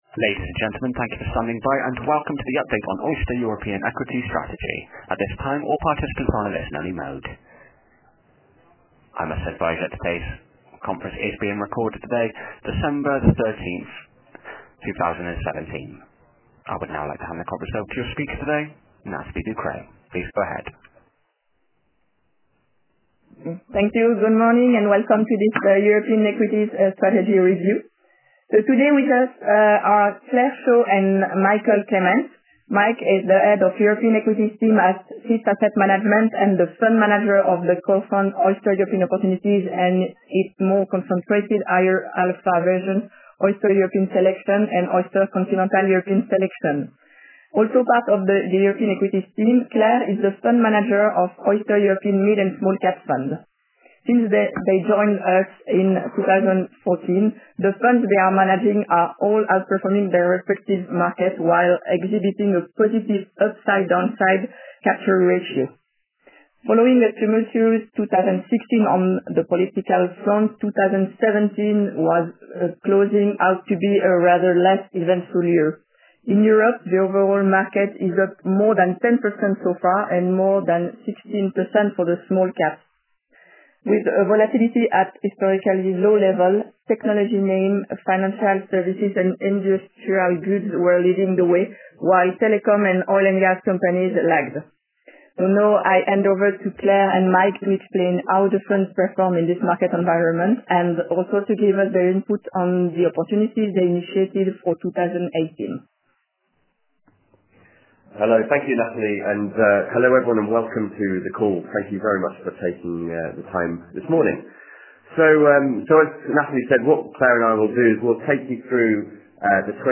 The presentation and a replay are available.